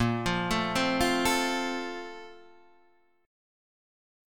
A# Major 7th Suspended 2nd